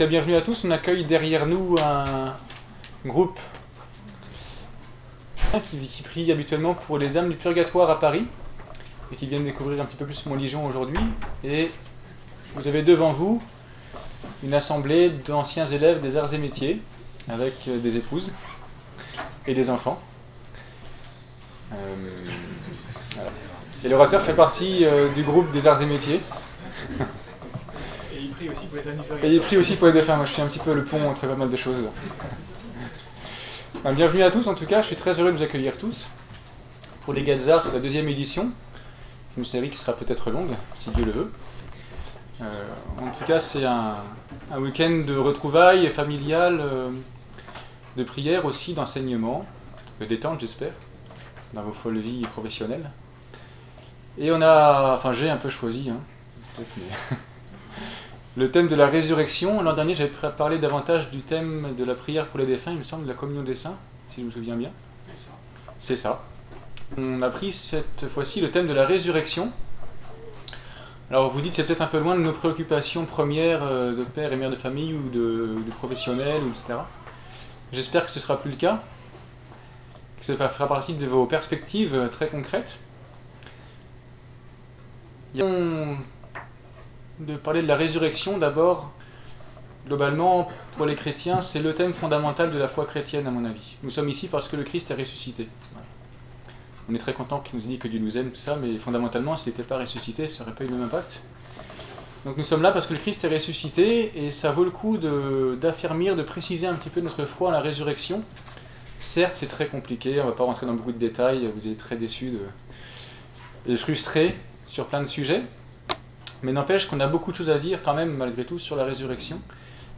En ce beau week-end des Rameaux, 33 Gadzarts, 60 participants en comptant leurs épouses et enfants, se sont retrouvés, à l’invitation de La Biffutière, dans le beau sanctuaire de Montligeon dédié aux âmes du purgatoire (près de Chartres).